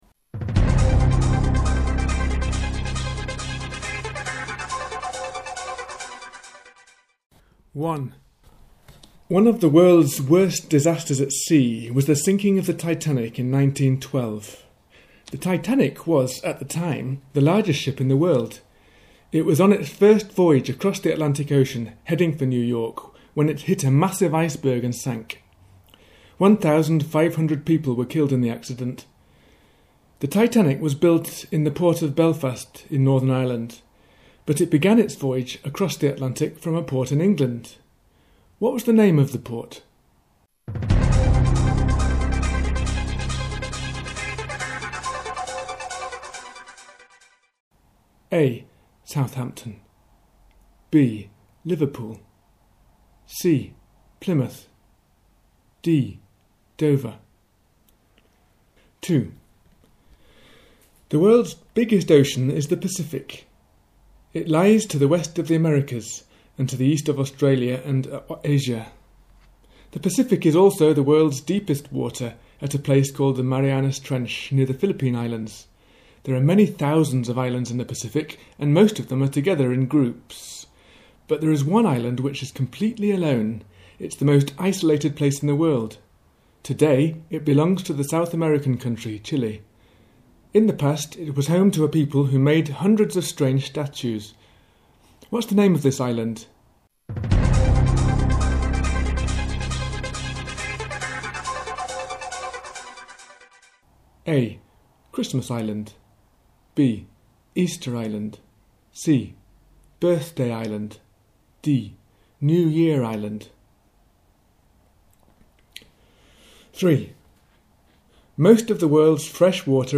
General knowledge quiz
The World of Water Quiz.mp3